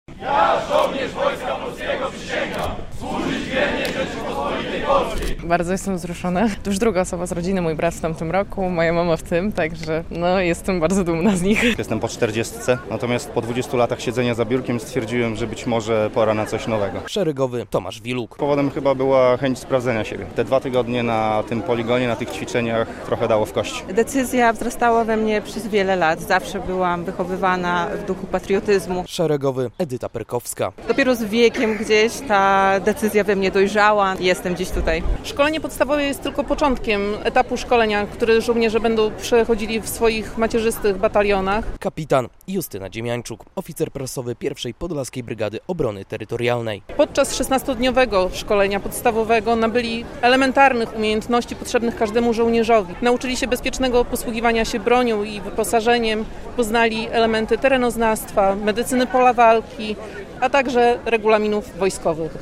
60 podlaskich terytorialsów złożyło przysięgę w Augustowie - relacja